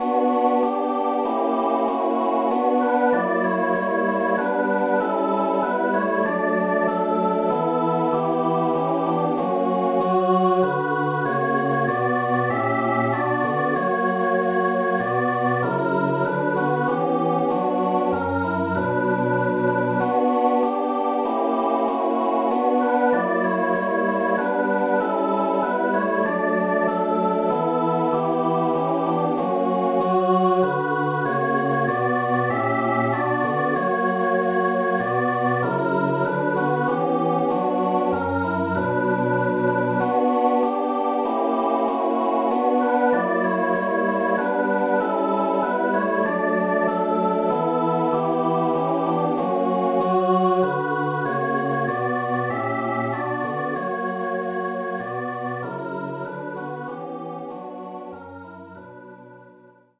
Quelle: MK 5, 242 Musikbeispiel: "Stabat Mater" (computergenerierte WAV-Datei)